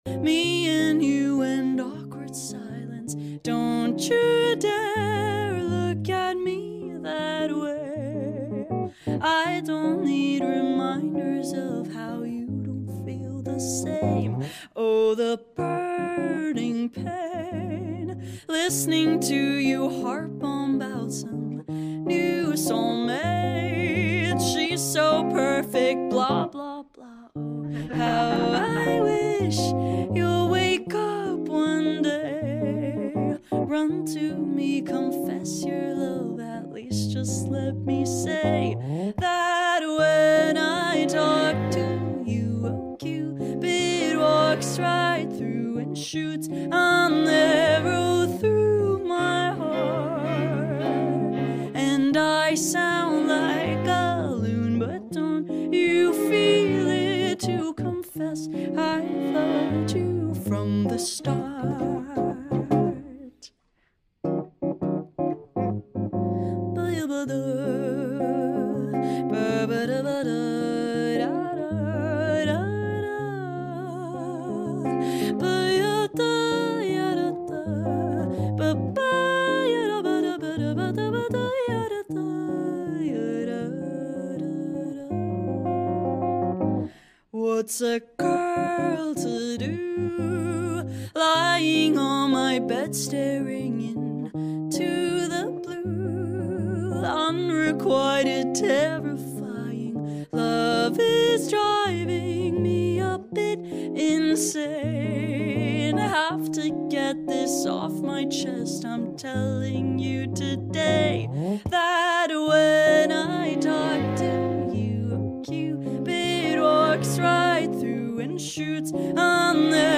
violinist at the back: 👁👄👁
The breath control is impeccable
mic needs more compression, but her singing is immaculate